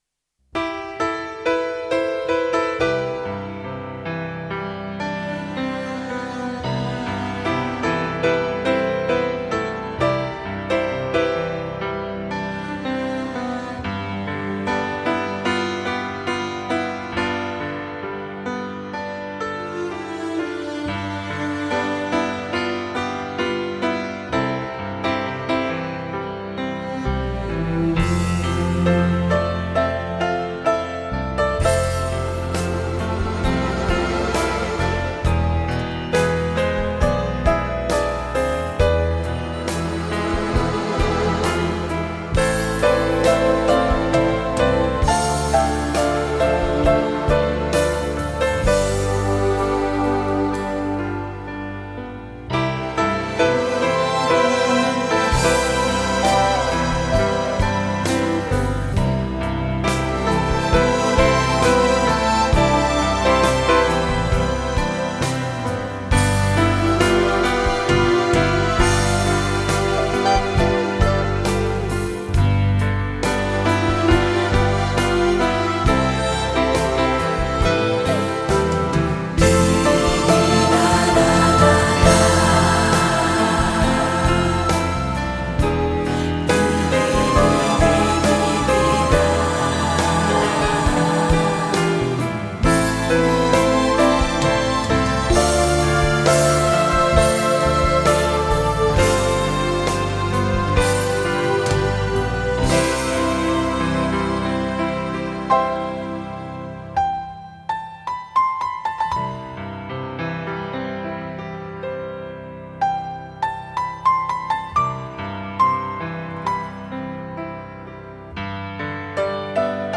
radiomarelamaddalena / STRUMENTALE / PIANO /